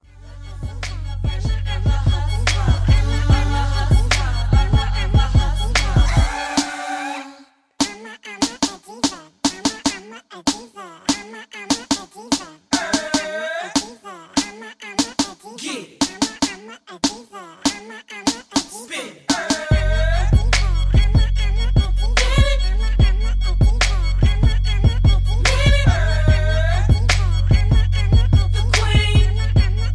(Key-Dbm) Karaoke MP3 Backing Tracks
Just Plain & Simply "GREAT MUSIC" (No Lyrics).